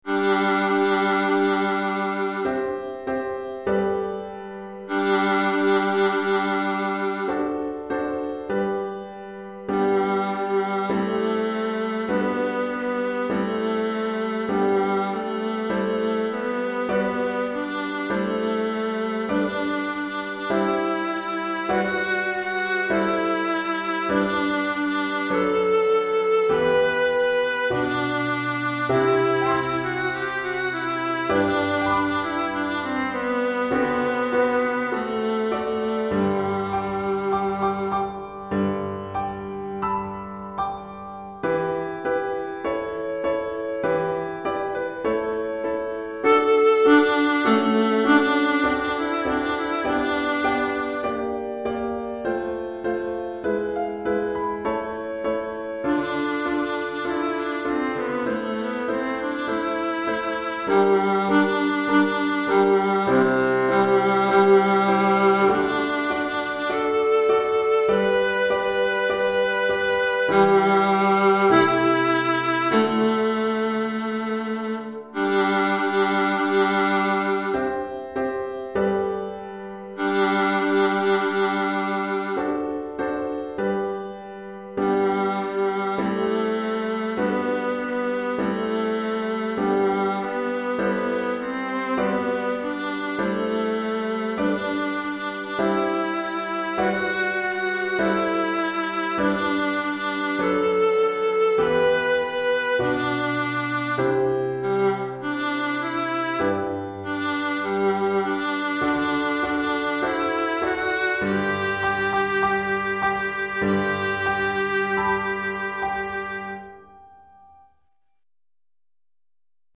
pour alto et piano DEGRE CYCLE 1 Durée